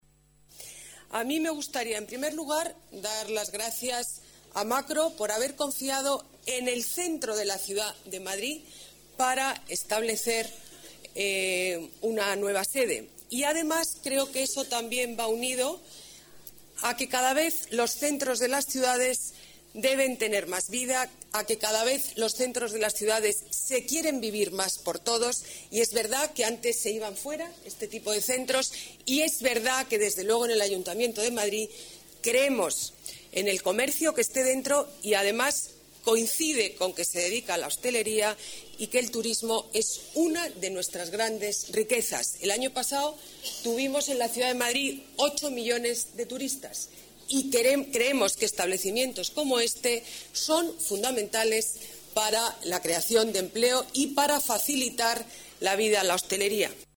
Nueva ventana:Botella en la inauguración de Makro en el paseo Imperial